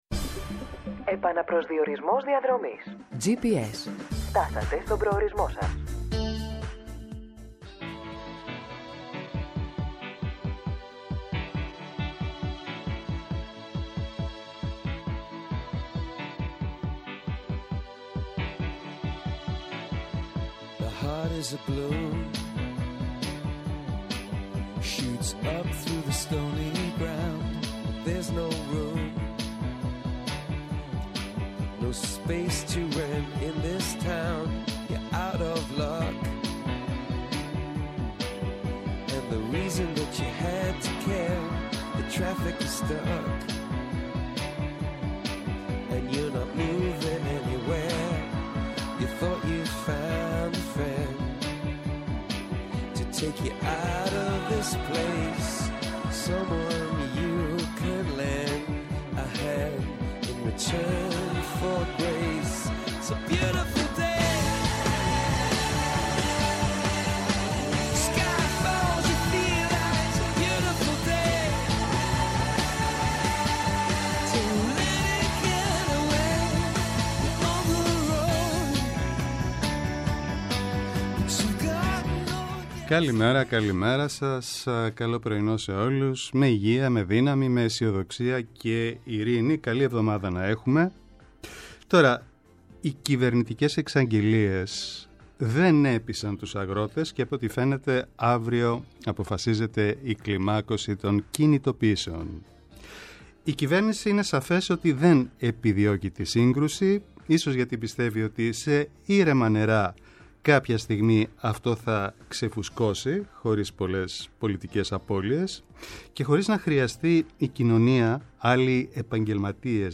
-O Νίκος Αλιβιζάτος ομότιμος καθηγητής Συνταγματικού Δικαίου του Πανεπιστημίου Αθηνών
-O Βασίλης Κόκκαλης, βουλευτής Λάρισας ΣΥΡΙΖΑ